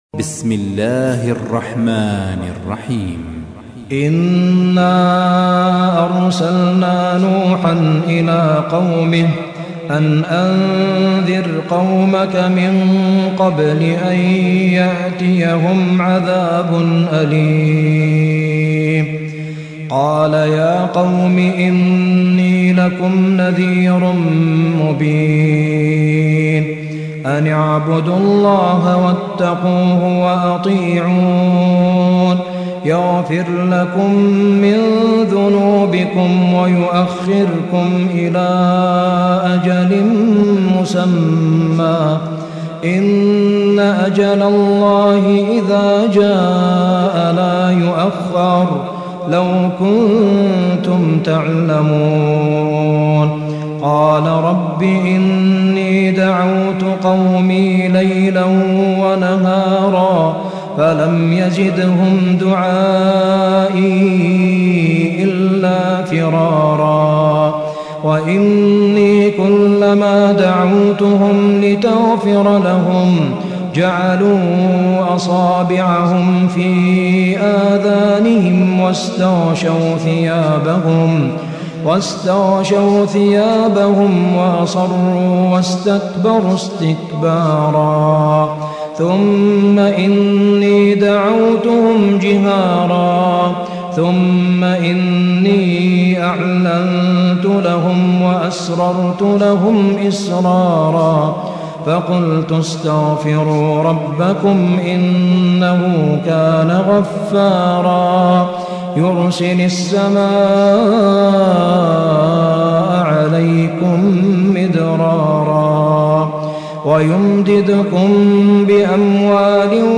71. سورة نوح / القارئ